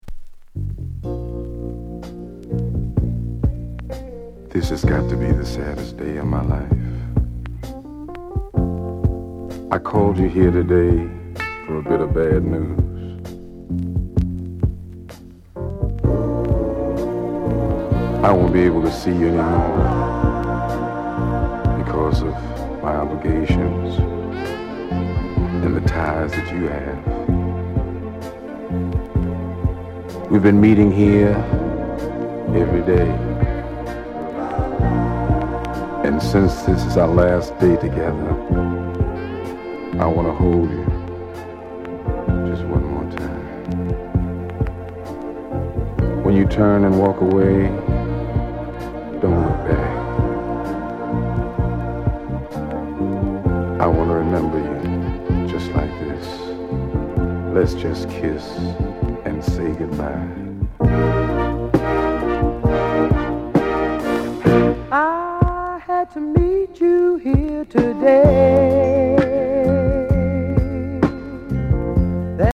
SOUND CONDITION VG
JAMAICAN SOUL